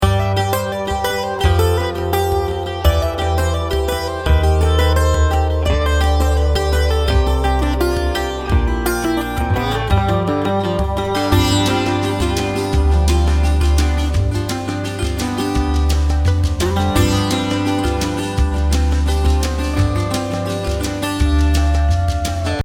ATTACHMENT11 Audio example 9 intro with melodic style Bansitar 446 KB MP3 Audio File (Tagged)